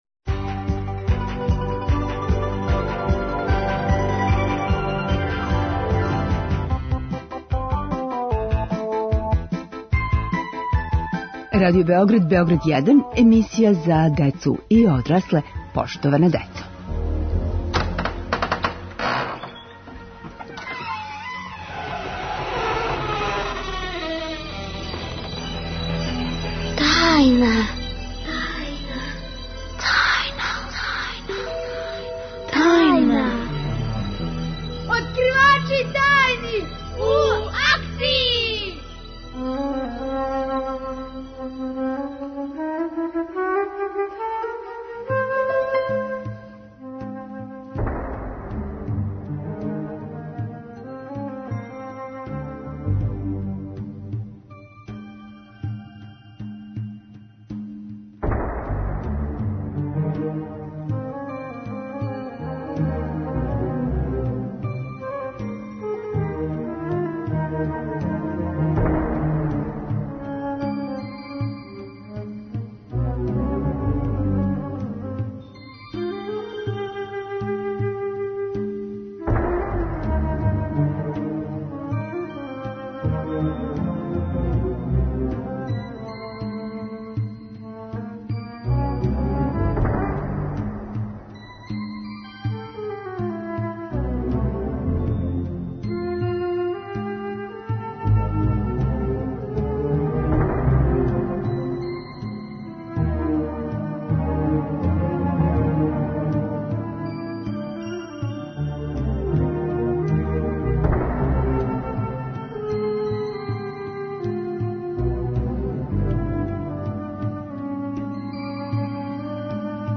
Данас је Светски дан хлеба и тим поводом откривамо све тајне ЗДРАВЕ ХРАНЕ. У томе нам помажу Здраволог, Заштолог, Зоотајнолог и шумски дописник.